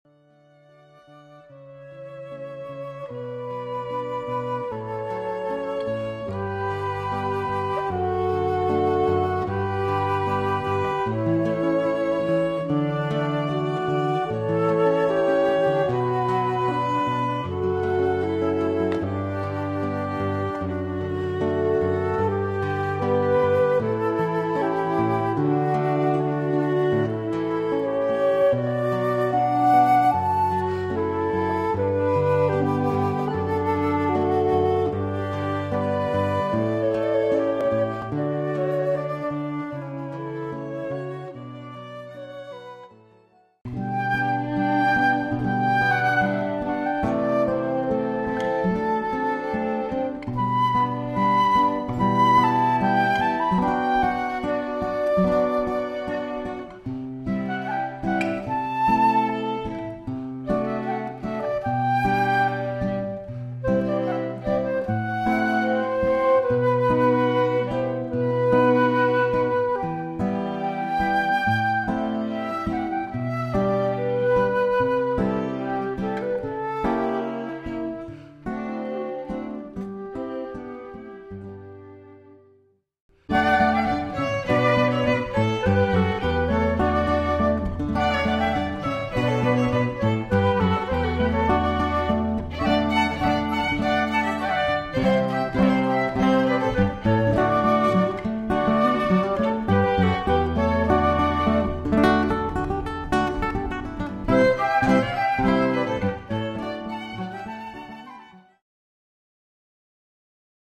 Live classical music creates a beautiful atmosphere for a church or outdoor ceremony. Our trio combines the warm textures of flute, violin and guitar playing a repertoire that includes all the greats; Bach, Mozart, Vivaldi, Handel, Beethoven, Ravel etc. Vocals are optional. We normally use a small amount of amplification to enhance the sound but we can perform acoustically if preferred.
classicalceremony.mp3